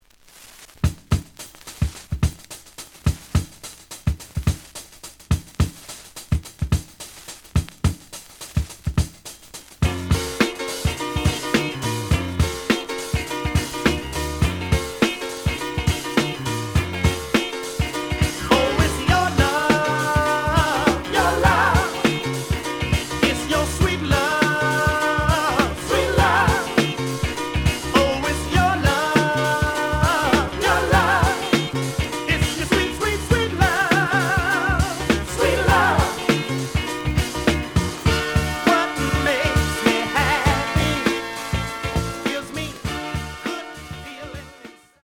The audio sample is recorded from the actual item.
●Genre: Funk, 70's Funk
Some noise on beginning of B side, but almost good.)